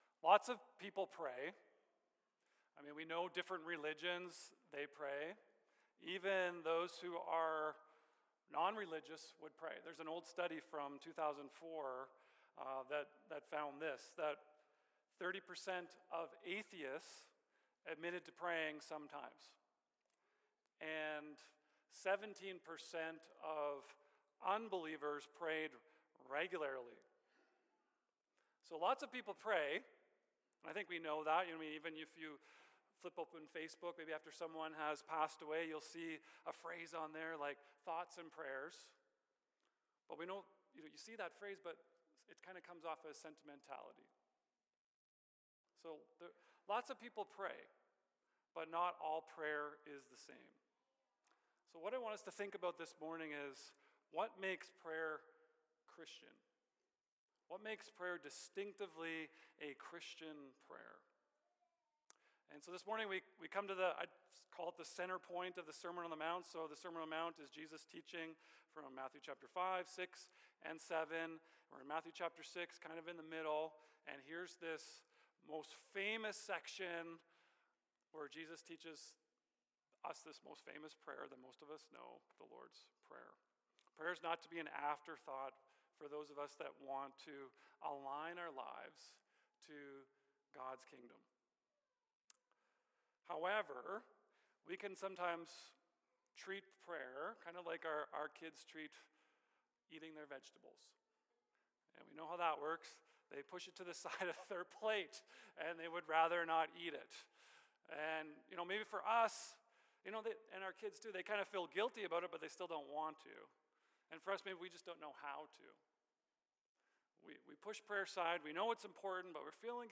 Sermons | Brooks Evangelical Free Church